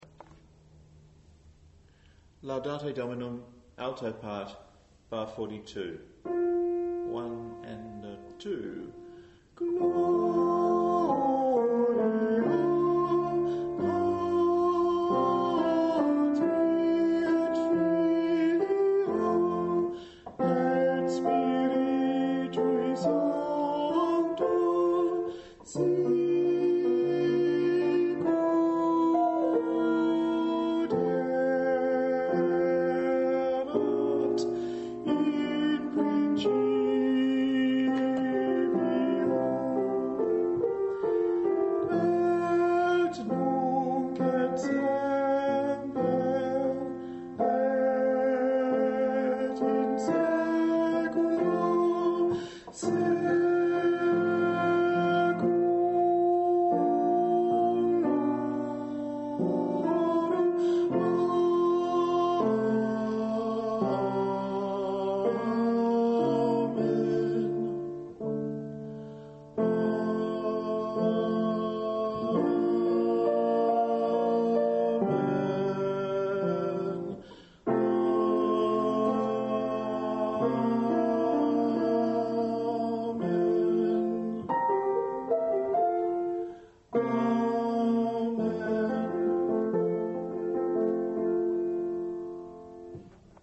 9.-MOZART_LaudateDominum_ALTO_from_bar_42.MP3